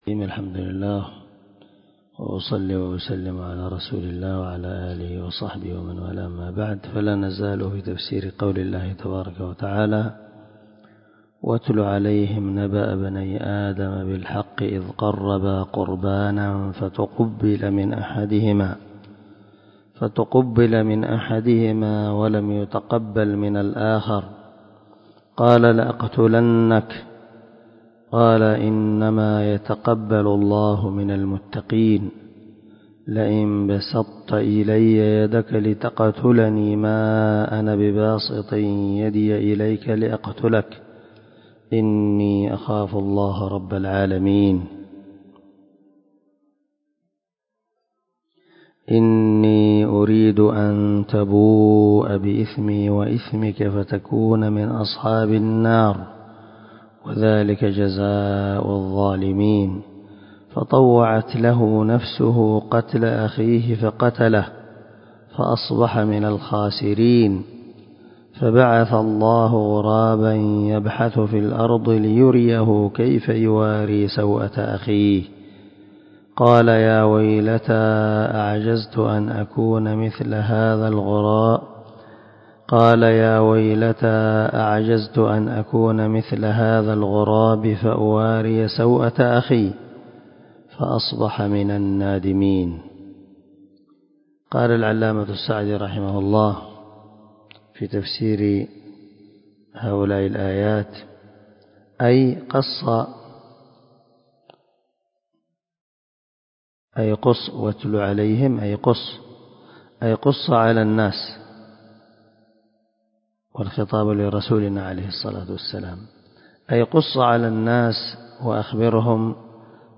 355الدرس 22 تابع تفسير آية ( 27 – 31 ) من سورة المائدة من تفسير القران الكريم مع قراءة لتفسير السعدي
دار الحديث- المَحاوِلة- الصبيحة.